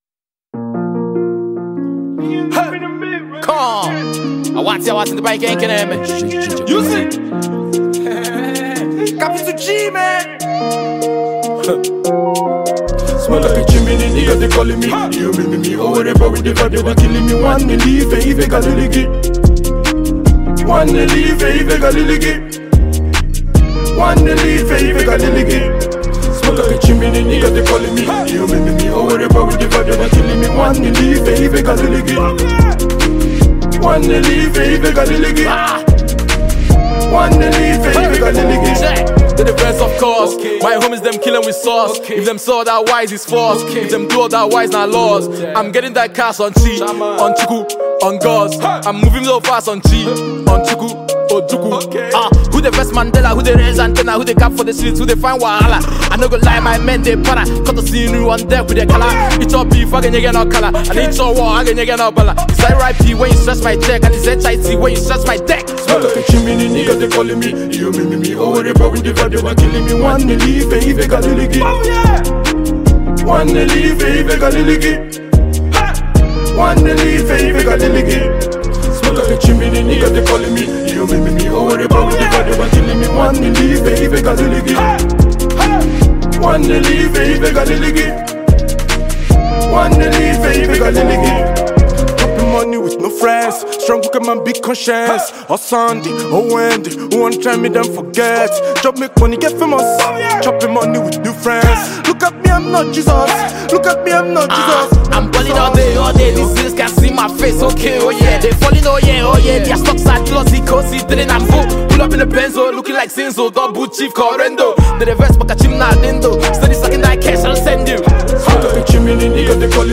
Highly Rated Nigerian Hardcore Rapper
Rap Single